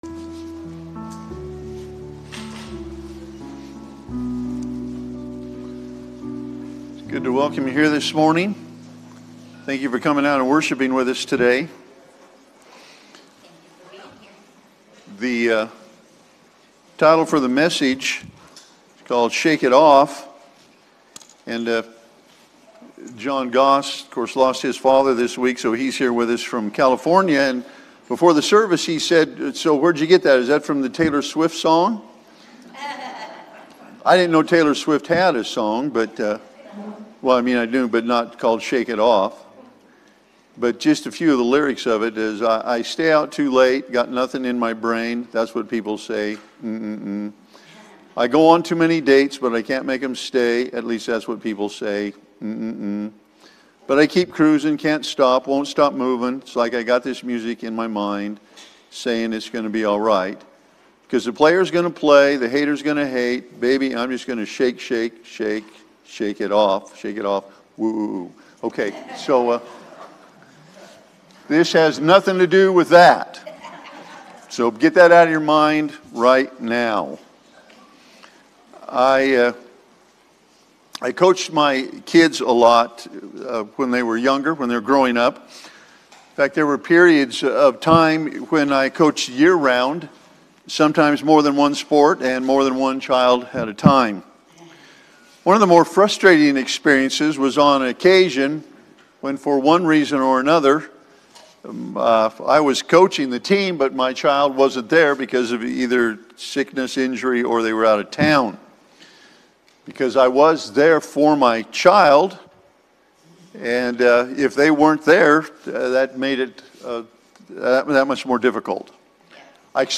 4-19-Sermon-Audio.mp3